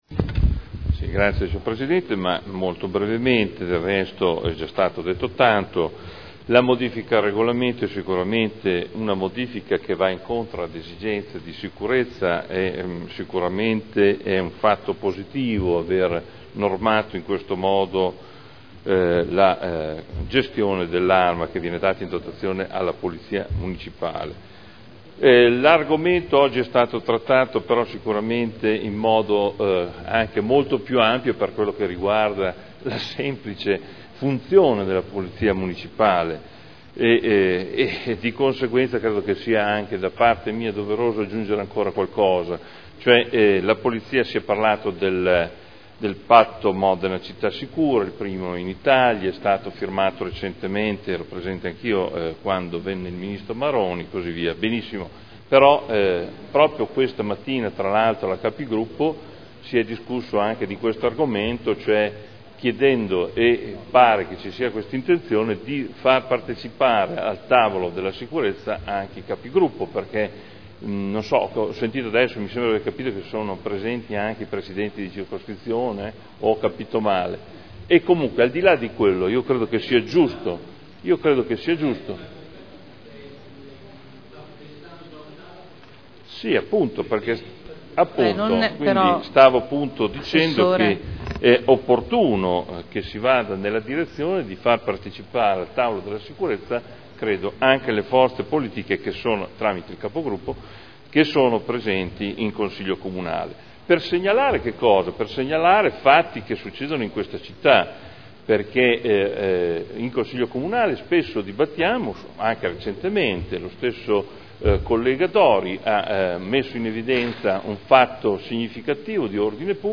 Seduta del 05/12/2011. Dichiarazione di voto.